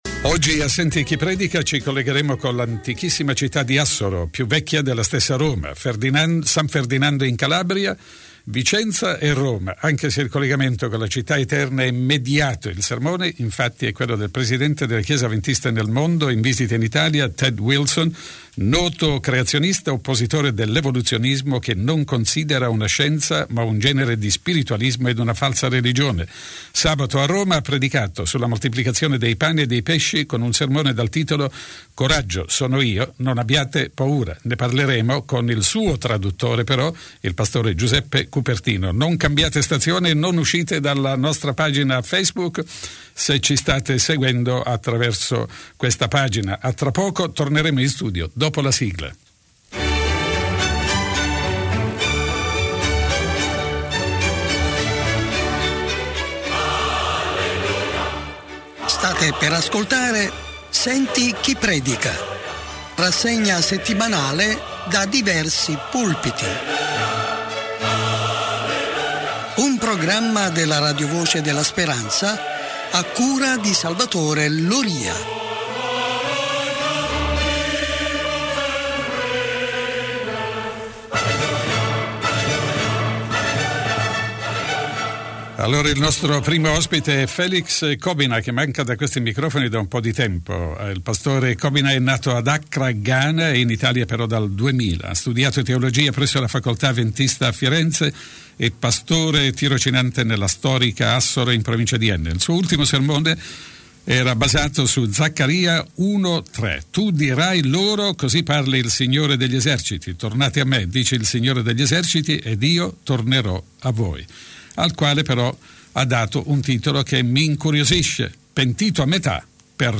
Rassegna settimanale da diversi pulpiti